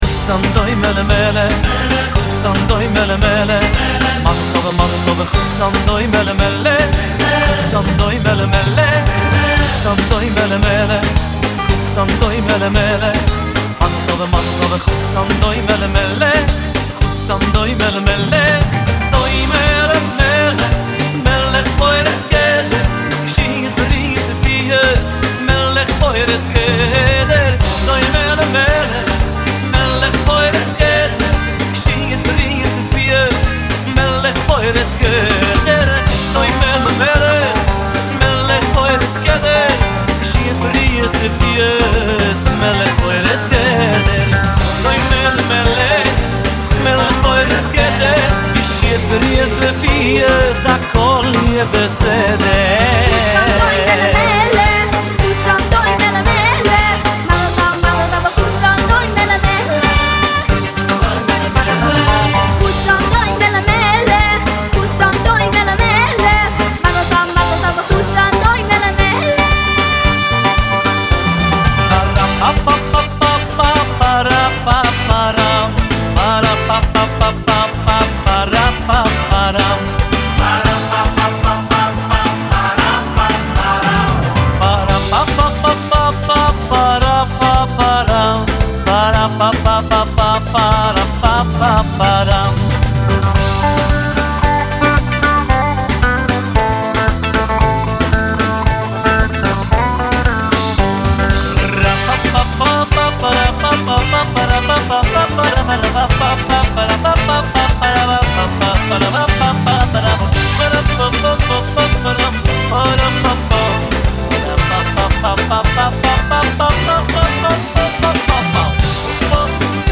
תשמעו איזה פתיח מזרחי מטורף הוא מכניס שם